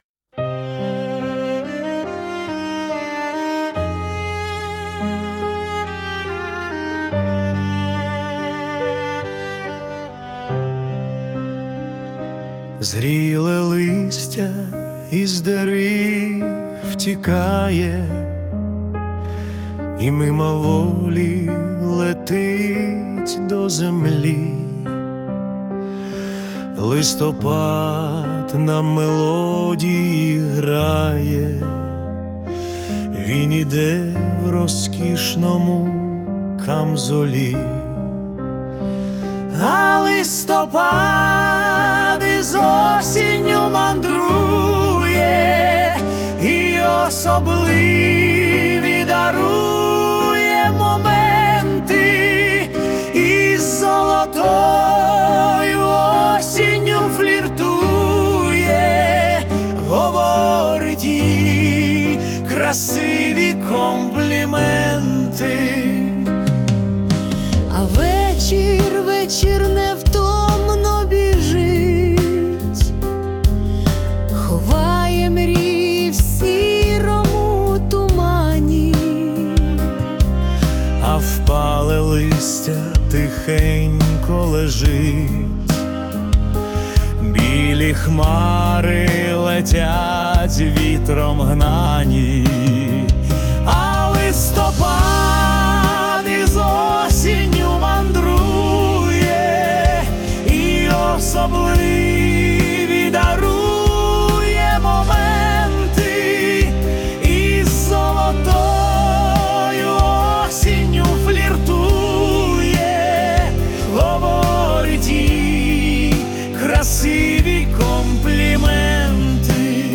СТИЛЬОВІ ЖАНРИ: Ліричний